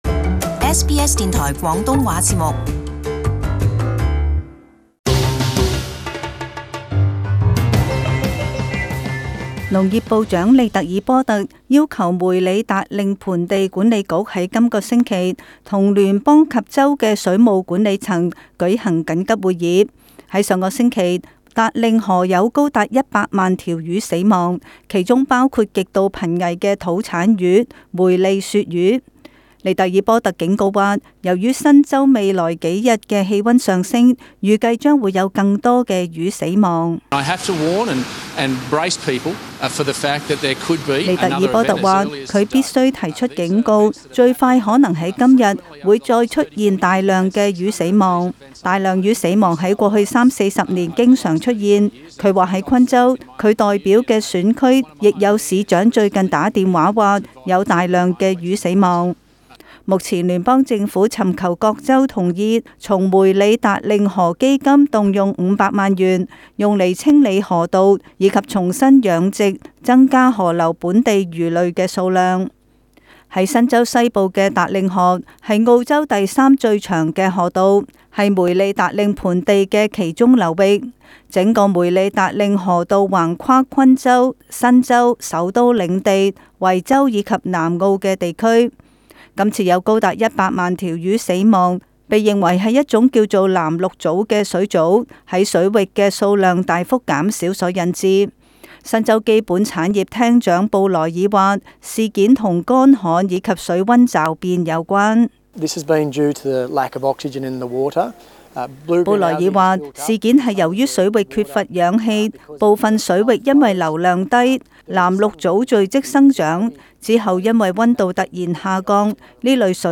【時事報導】